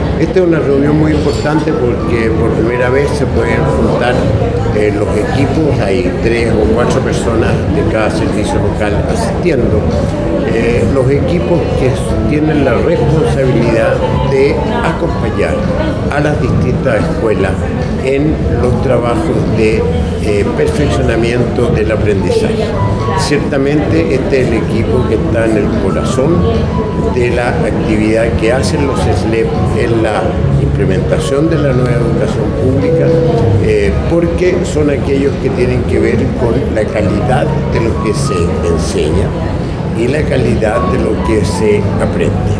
Rodrigo-Egana-Baraona-director-Educacion-Publica.mp3